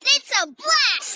Speaking Voice Of Ticket Blaster Kid From Chuck E Cheese